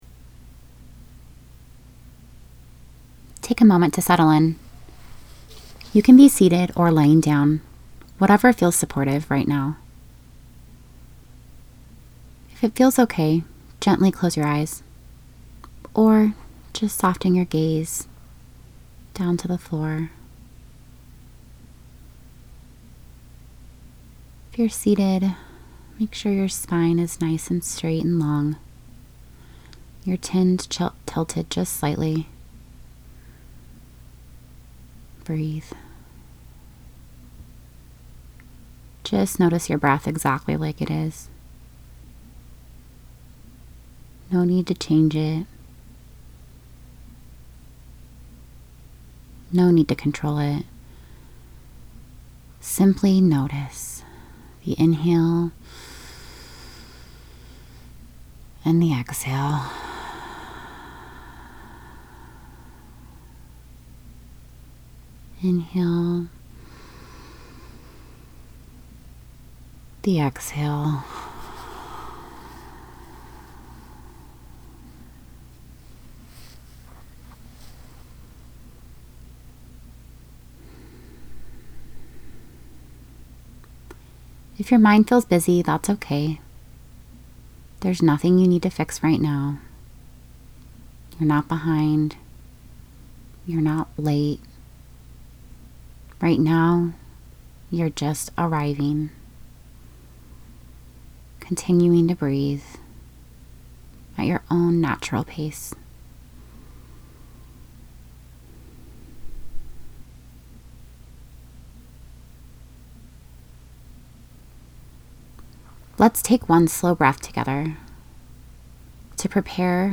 12 Minute Sol Meditation.mp3